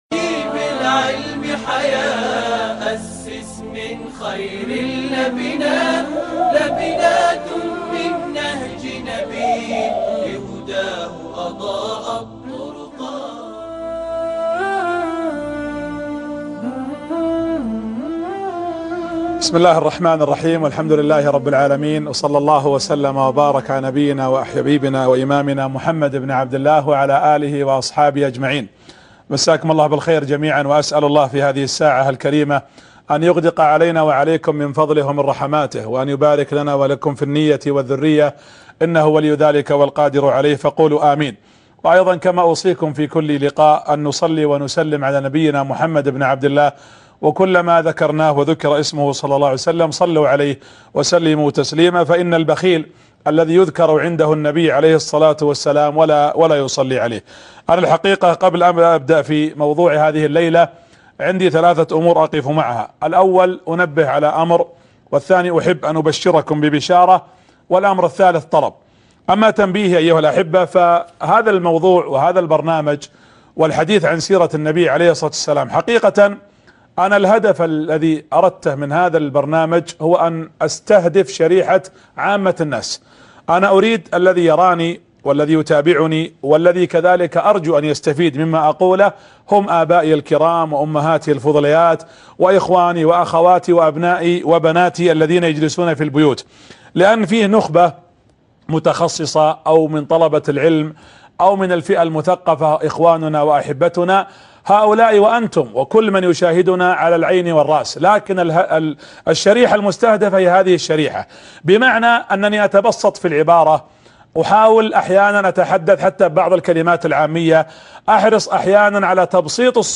السيرة النبوية> الحلقة الثاسعة درس السيرة النبوية